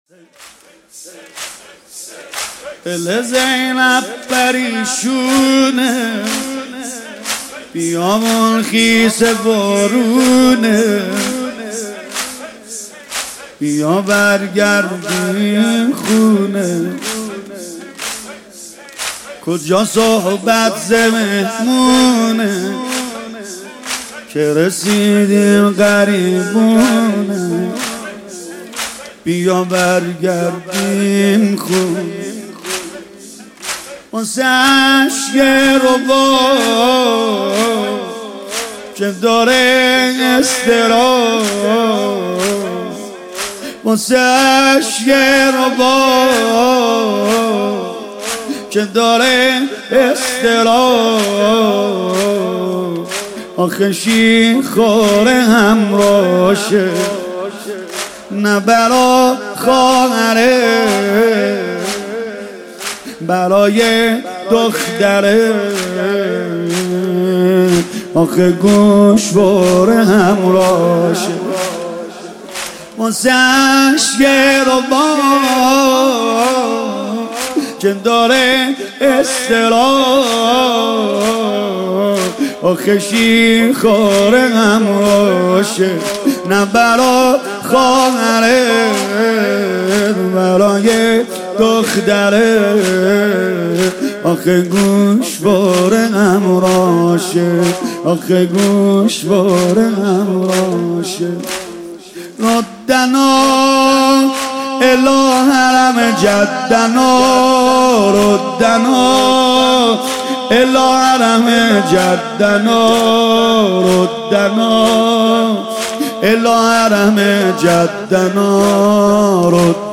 شب دوم محرم97